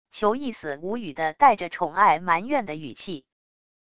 求意思无语的带着宠爱埋怨的语气wav音频